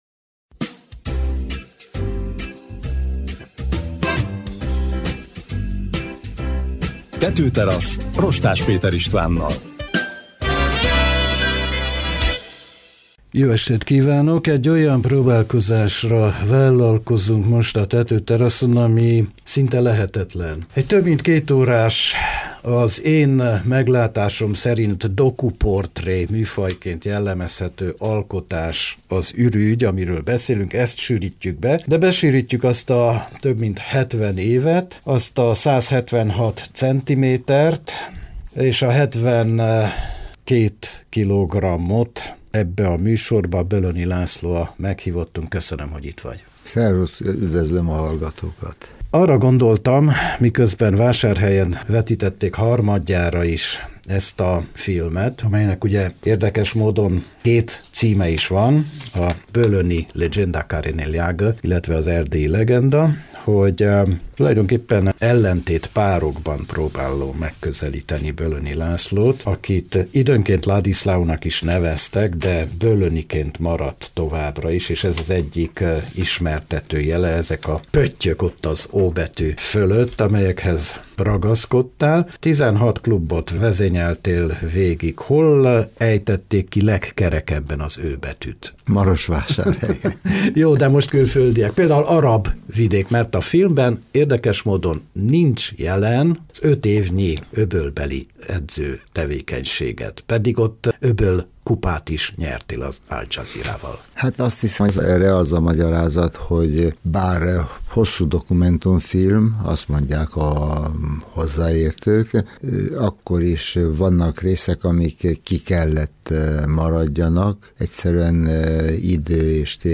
Fegyelmezett, diszkrét, és maximalizmusra törekvő - Bölöni László jött el a Tetőteraszra egy bő félidőre.